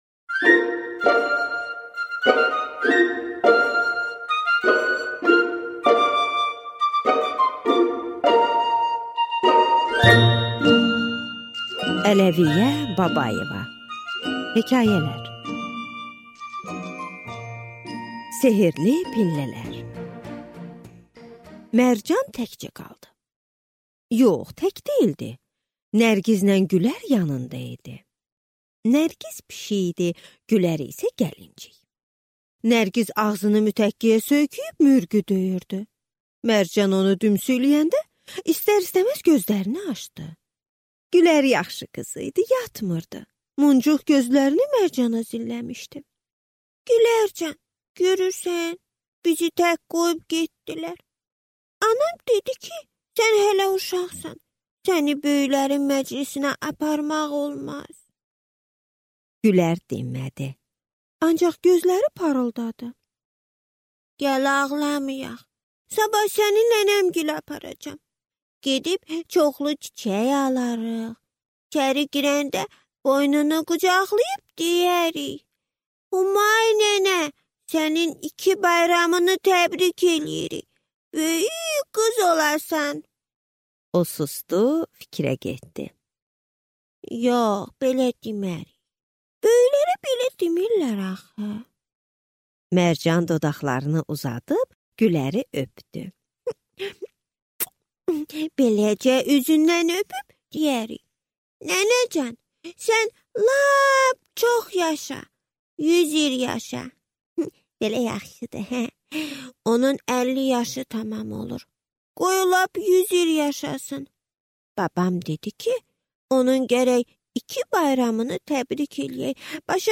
Аудиокнига Hekayə və nağıllar | Библиотека аудиокниг